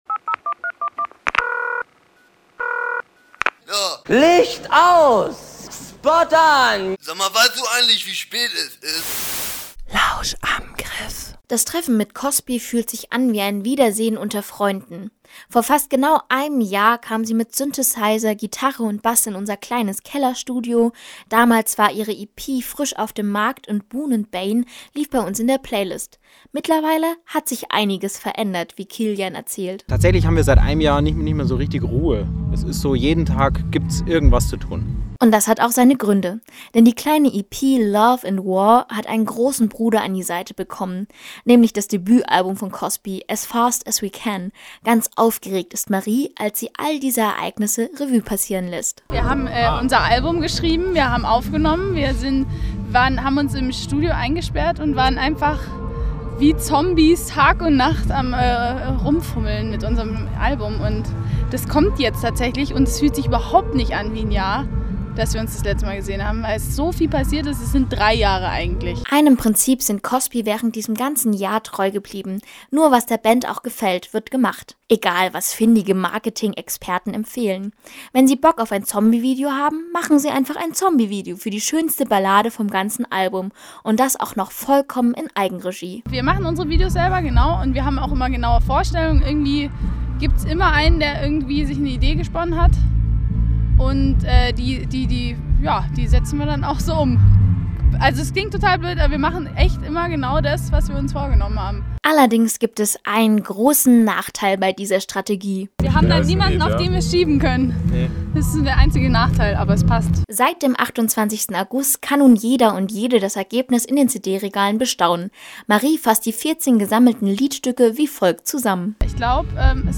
Cosby nach einem Jahr erneut zu interviewen, war wie ein Treffen unter alten Freunden.